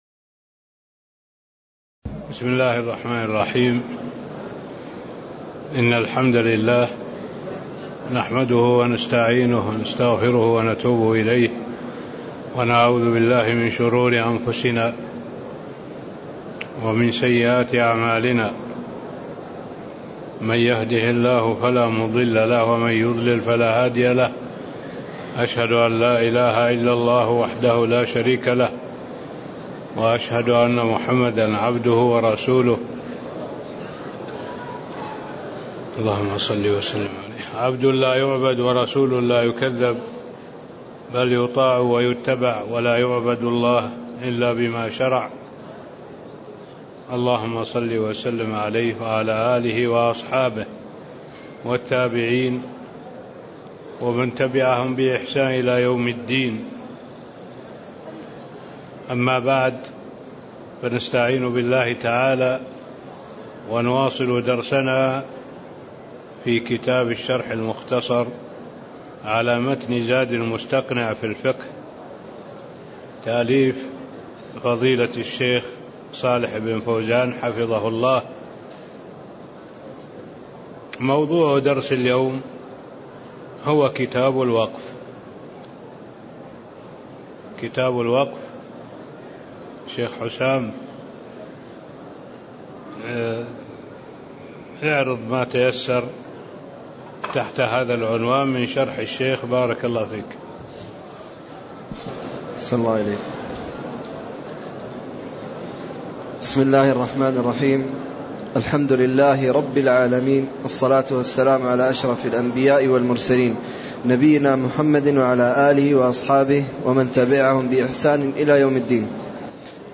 تاريخ النشر ١٤ جمادى الأولى ١٤٣٥ هـ المكان: المسجد النبوي الشيخ